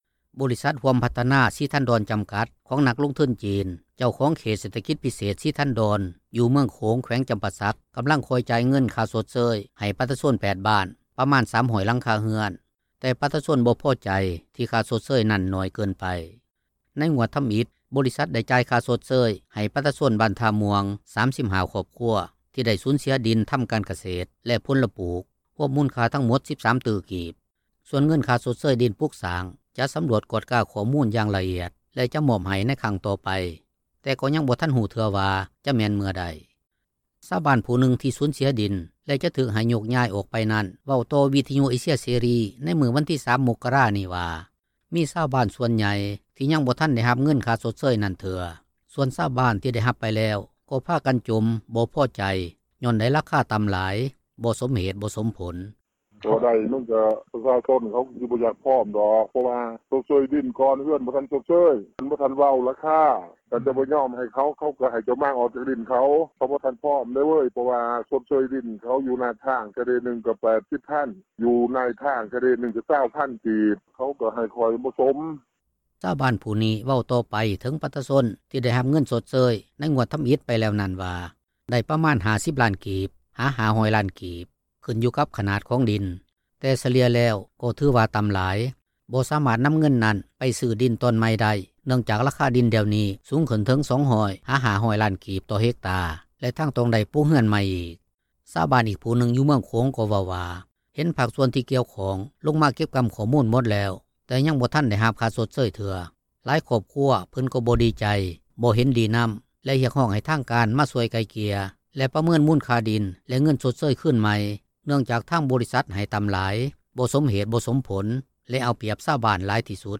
ຊາວບ້ານຜູ້ນຶ່ງ ທີ່ສູນເສັຍດິນ ແລະຈະຖືກໃຫ້ຍົກຍ້າຍອອກໄປນັ້ນ ເວົ້າຕໍ່ວິທຍຸເອເຊັຽເສຣີ ໃນມື້ວັນທີ 3 ມົກກະຣານີ້ວ່າ ມີຊາວບ້ານສ່ວນໃຫຍ່ ທີ່ຍັງບໍ່ທັນໄດ້ຮັບເງິນຄ່າຊົດເຊີຍນັ້ນເທື່ອ. ສ່ວນຊາວບ້ານ ທີ່ໄດ້ຮັບໄປແລ້ວ ຈົ່ມບໍ່ພໍໃຈ ຍ້ອນໄດ້ລາຄາຕໍ່າ, ບໍ່ສົມເຫດບໍ່ສົມຜົລ.